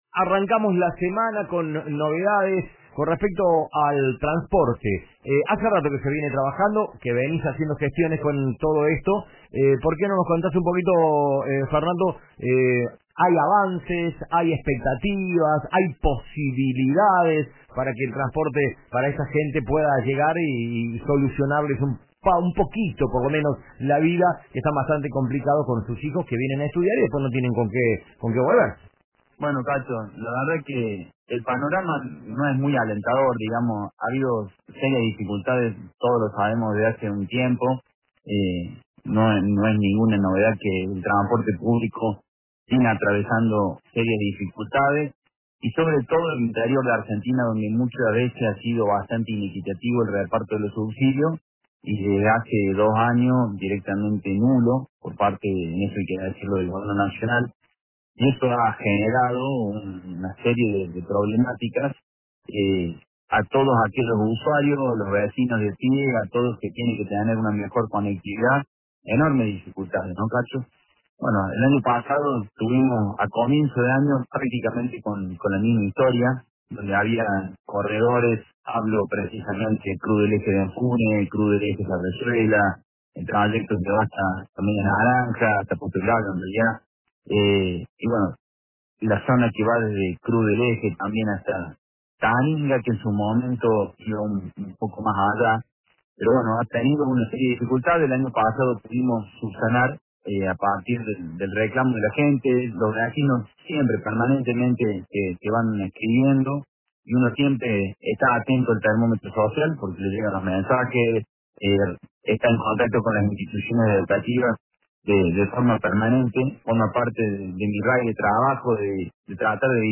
El legislador del departamento Cruz del Eje, Fernando Luna, confirmó en diálogo con Cadena Centro FM que desde la próxima semana volverá a funcionar el corredor interurbano Cruz del Eje – Taninga, un servicio largamente reclamado por los usuarios del noroeste cordobés.
“Se logró recuperar este corredor tan importante, que une a los departamentos Cruz del Eje, Minas y Pocho, y que era muy necesario para estudiantes, docentes, personal de salud y vecinos en general”, expresó Luna durante la entrevista.